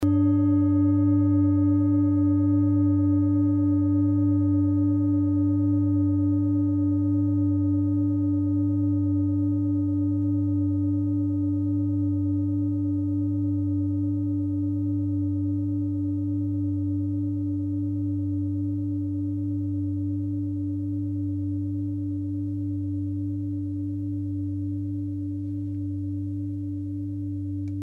Klangschale Bengalen Nr.22
Klangschale-Gewicht: 1800g
Klangschale-Durchmesser: 25,5cm
Sie ist neu und wurde gezielt nach altem 7-Metalle-Rezept in Handarbeit gezogen und gehämmert.
(Ermittelt mit dem Filzklöppel oder Gummikernschlegel)
klangschale-ladakh-22.mp3